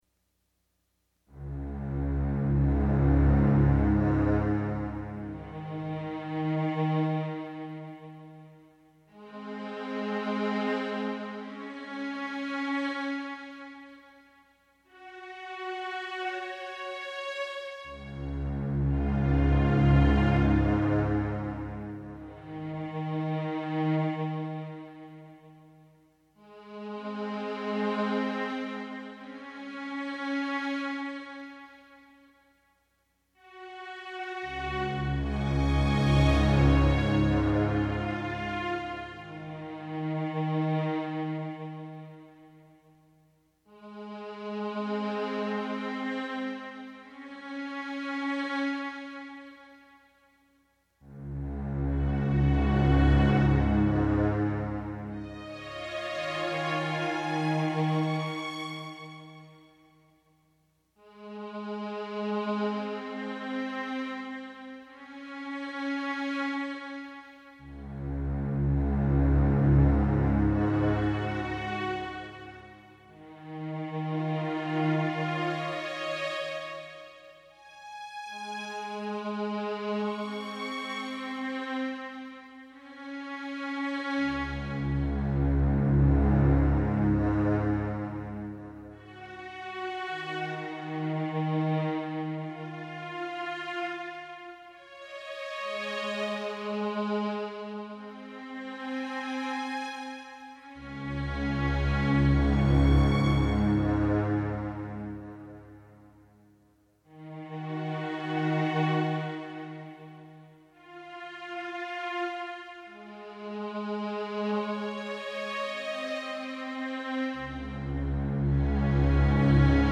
Continuum one is an ambient piece that is just the synthesizer part to my electroacoustic composition A Peace. This algorithmically-generated music was created using a single patch with complex modulation routings on a Kurzweil K2000 keyboard.
This algorithmic patch — which I dubbed “Continuum one” — creates non-repeating music in an ambient style by repeating each note the performer depresses on the keyboard at a different rate and will play itself for an unlimited duration until it is turned off by the performer. So, in 2006 I recorded just the synthesizer part for over 70 minutes to create the CD-length piece: Continuum one .